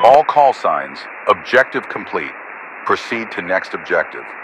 Radio-commandObjectiveComplete1.ogg